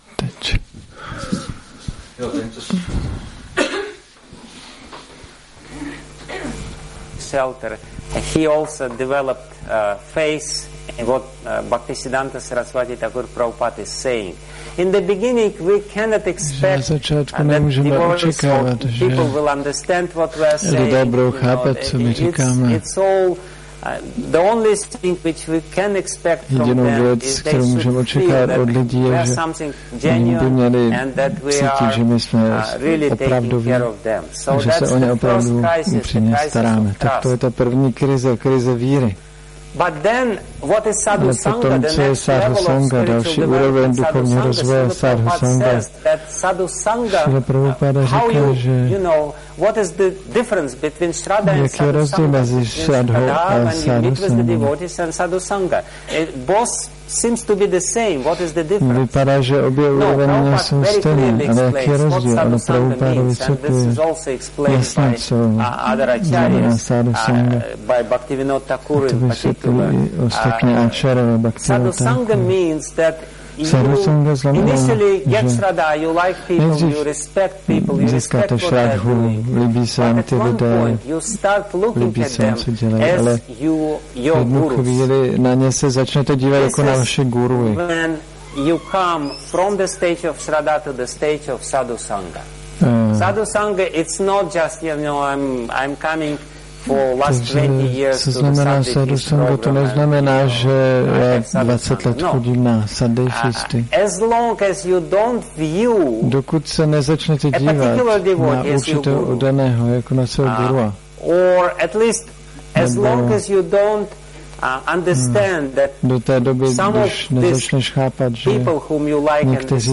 Seminář Krize víry 2